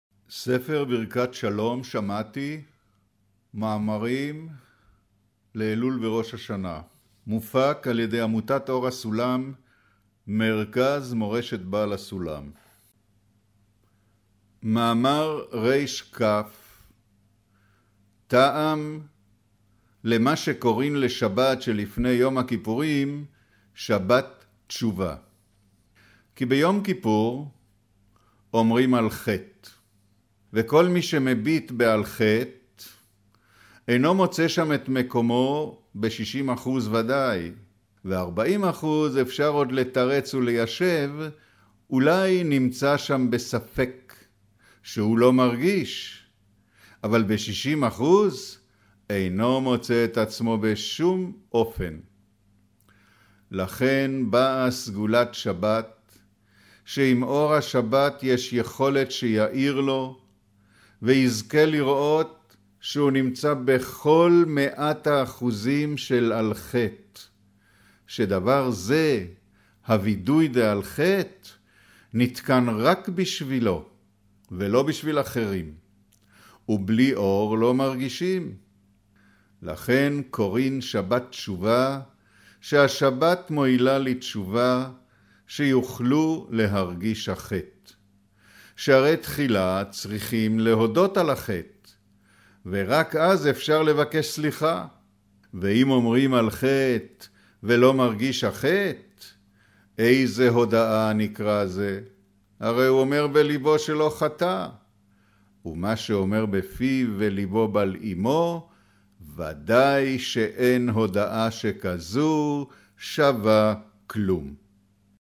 אודיו - קריינות מאמר